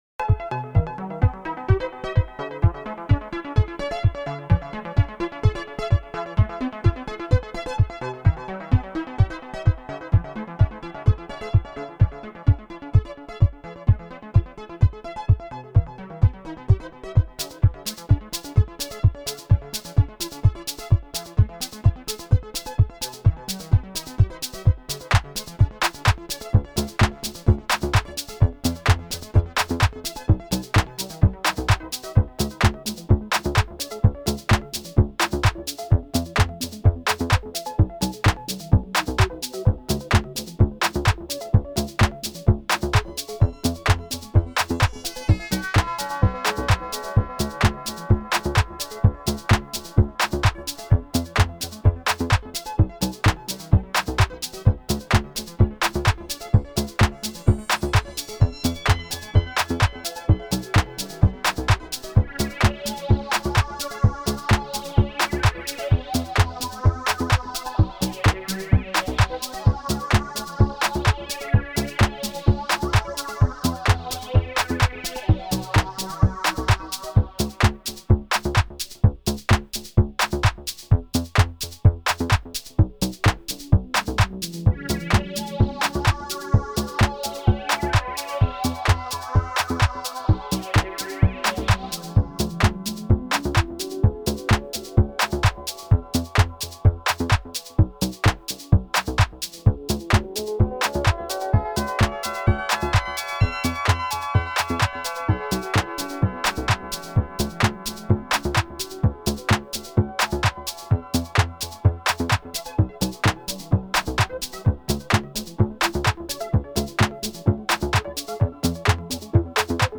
One Synth - One Voice - Monophonic Synth Challenge